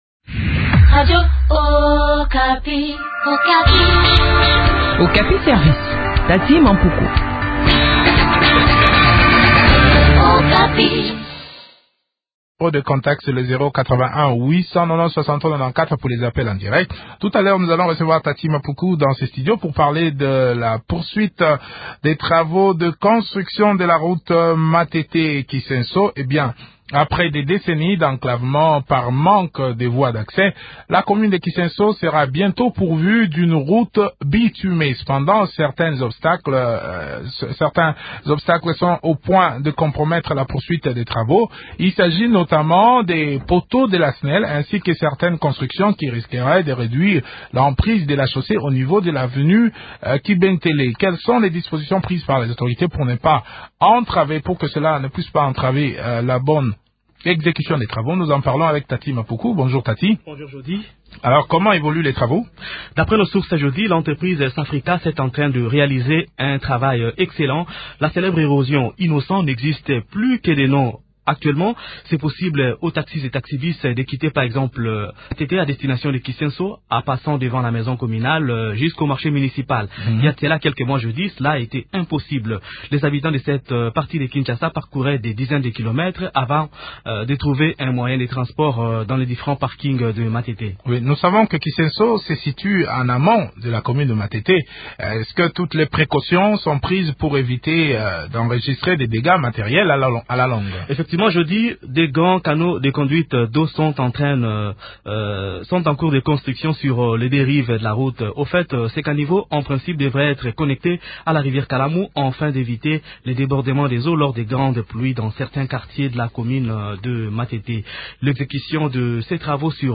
fait le point avec Bailon Gaibene , bougmestre de la commune de Kisenso.